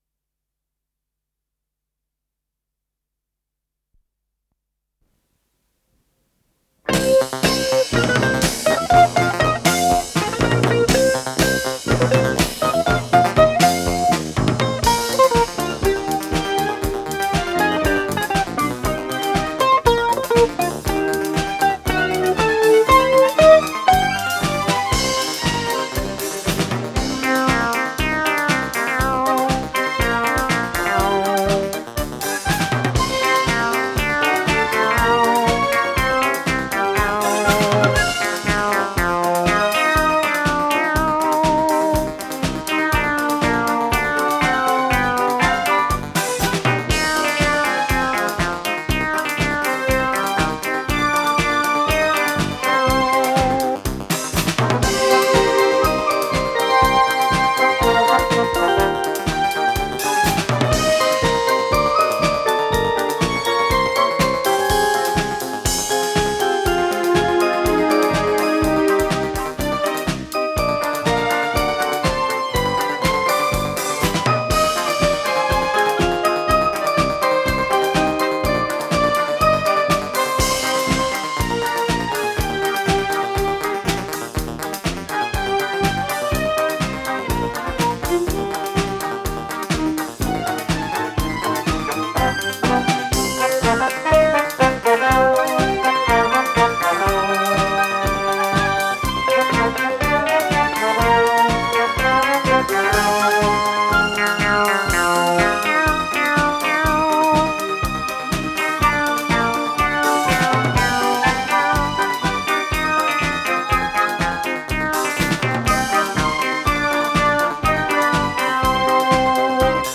с профессиональной магнитной ленты
ПодзаголовокЗаставка, до минор
ВариантДубль моно